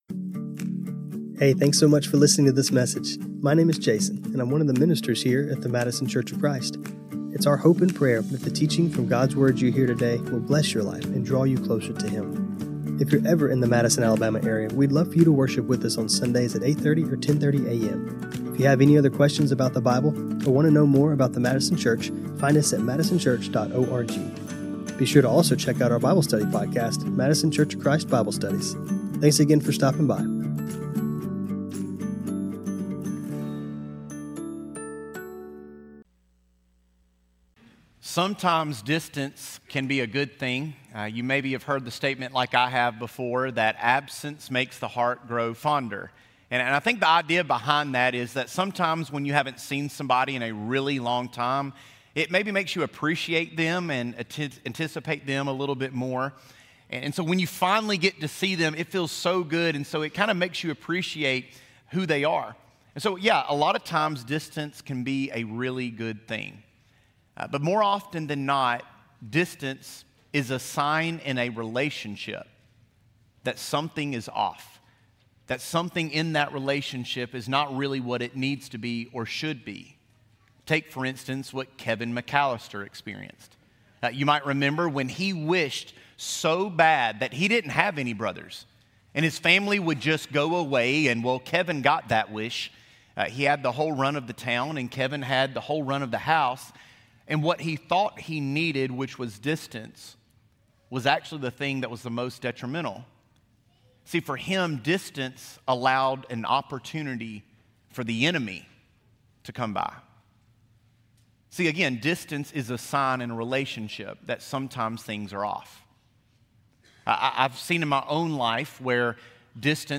The lesson today begs the question: how closely are you following Jesus and are you covered in the dust of the Rabbi? John 6:66-69 This sermon was recorded on Feb 1, 2026.